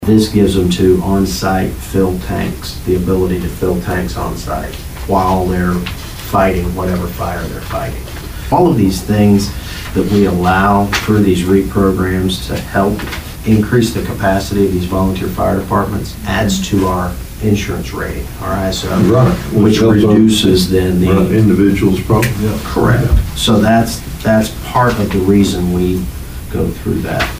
Commissioners Mitch Antle and Mike Dunlap discuss what an air cascade system is and the benefits to